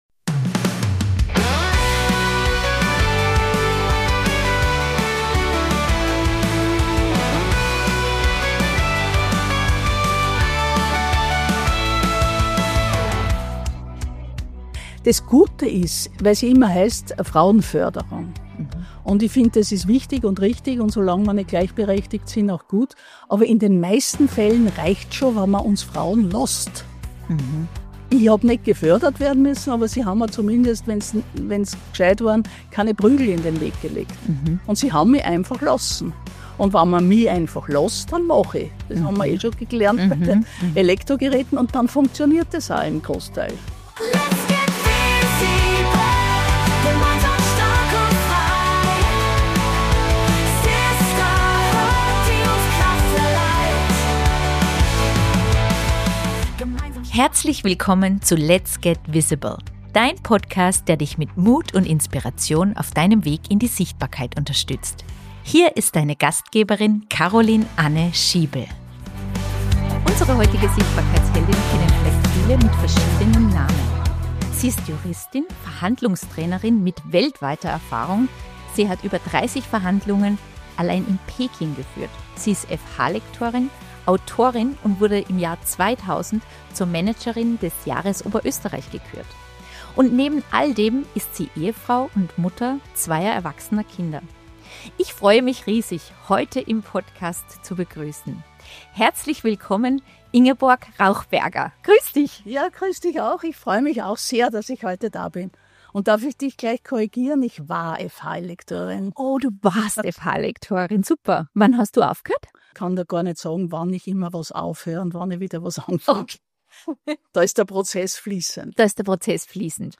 Ein homrvolles Gespräch über mutige Karrierewege, Sichtbarkeit mit Maß, und das Spiel mit Identitäten in der Literatur.